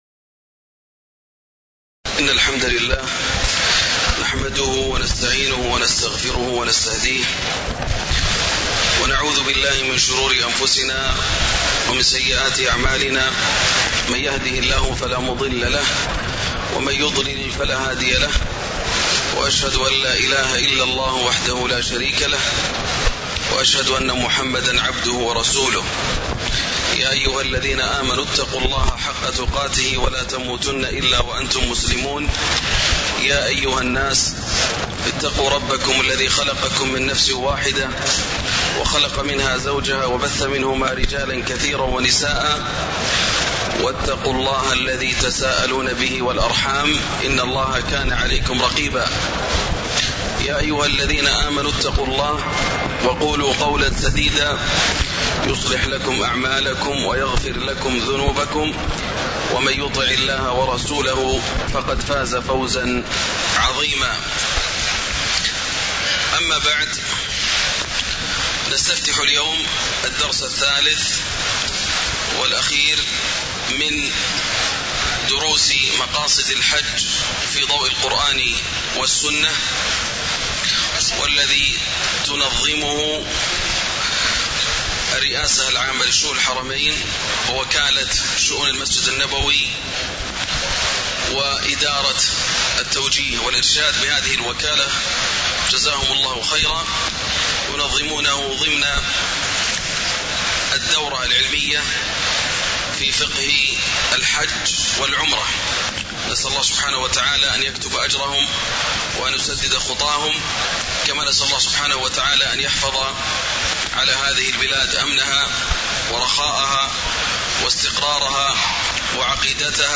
تاريخ النشر ٢٤ ذو القعدة ١٤٣٩ هـ المكان: المسجد النبوي الشيخ: فضيلة الشيخ ياسر الدوسري فضيلة الشيخ ياسر الدوسري المقصد الثاني من مقاصد الحجّ وهو التقوى (03)